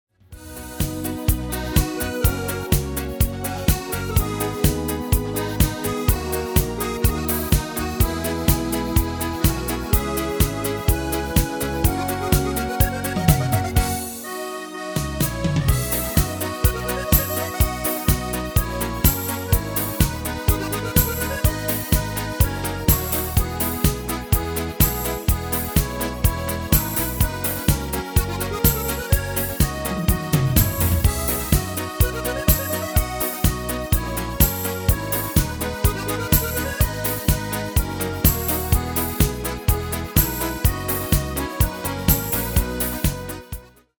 Genre: Nederlands amusement / volks
Toonsoort: B/C
- Vocal harmony tracks